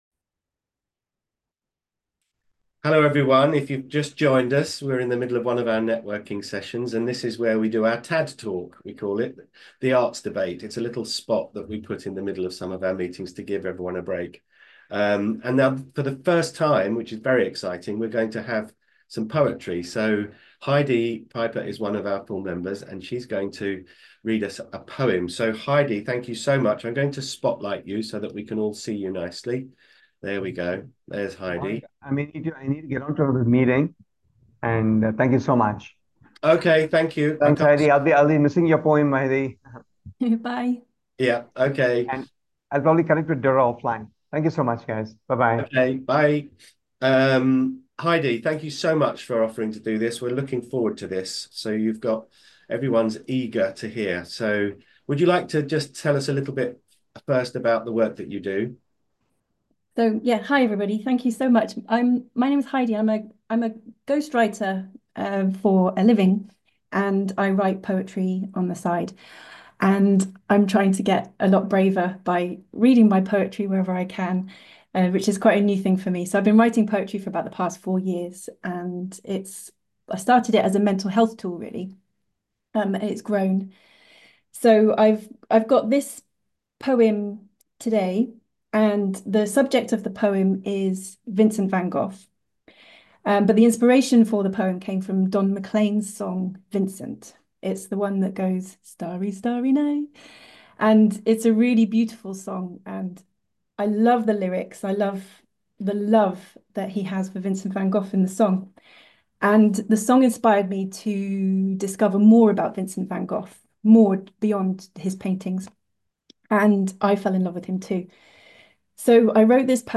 A Poetry Reading
poetry reading.